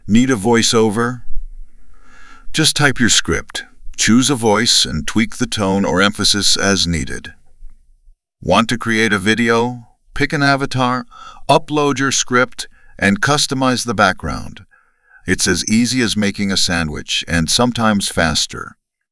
Its voiceovers are a far cry from the robotic tones of older technologies. Instead, they sound human—natural inflections, emotional depth, and all.
Synthesysscript.mp3